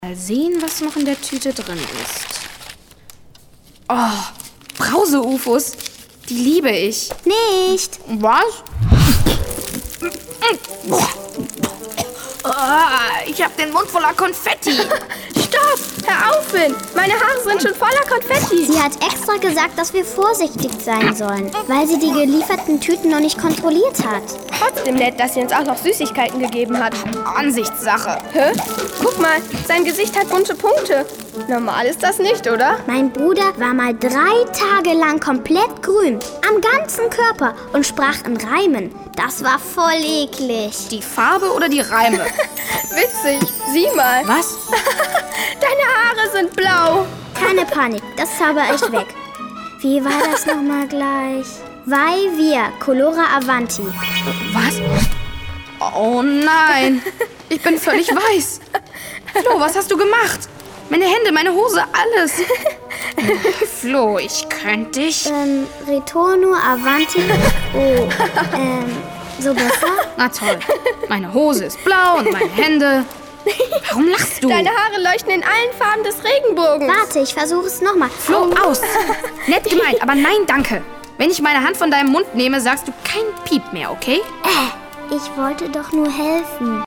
Audio Drama / Hörspiel
Contendo Media, das Team hinter den hochgelobten und mehrfach prämierten Jugendkrimihörspielen „Team Undercover“ und dem ebenso hochgelobten Märchenhörspiel „Drei Haselnüsse für Aschenbrödel“ sorgt mit seiner zauberhaften und liebevollen Inszenierung mit hochkarätiger Besetzung und eigens für die Serie komponiertem Orchester-Soundtrack für ein ganz besonderes Hörerlebnis für Jung und Alt!
Erzählerin – Judy Winter